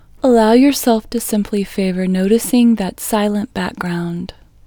LOCATE IN English Female 21